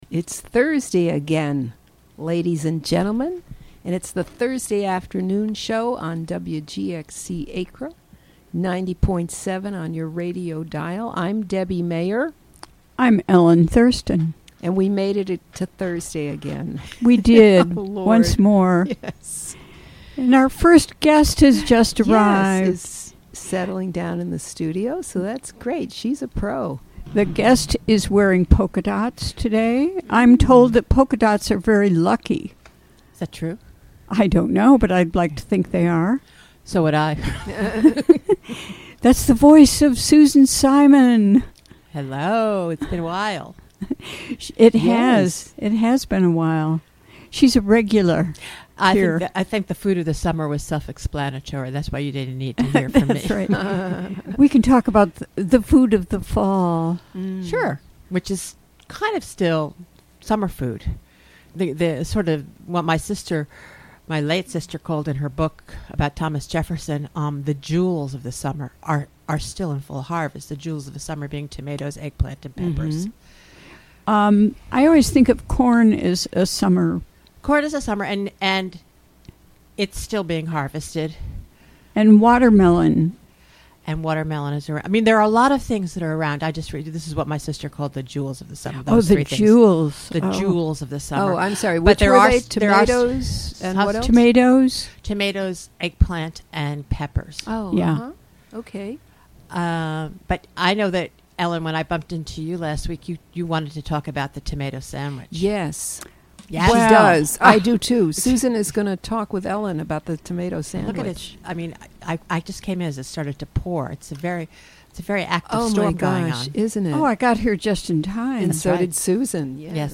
Recorded during the WGXC Afternoon Show on September 7, 2017.